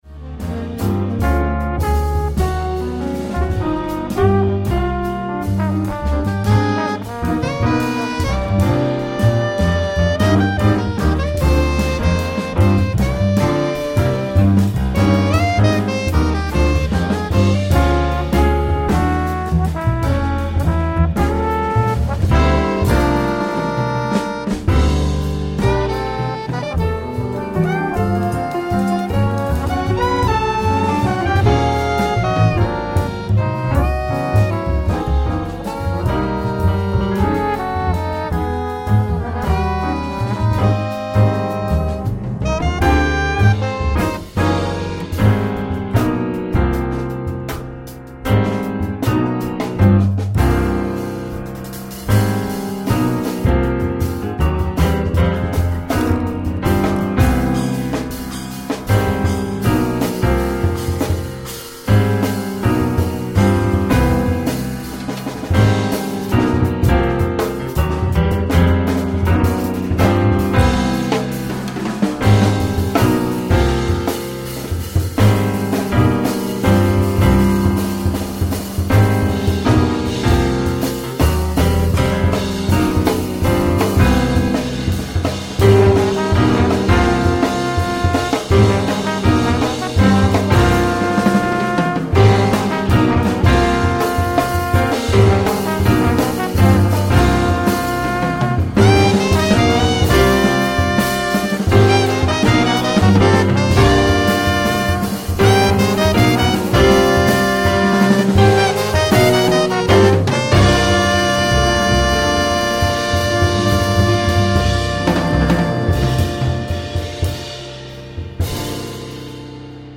trombone
tenor sax
piano
bass
drums